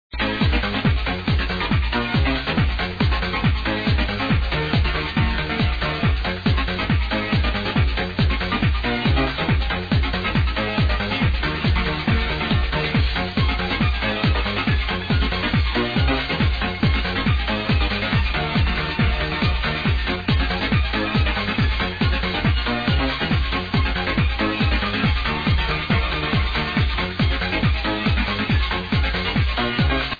sounds a little bit french to me - happy days